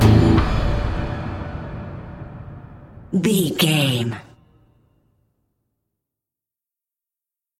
Aeolian/Minor
synthesiser
drum machine
ominous
dark
suspense
haunting
creepy
spooky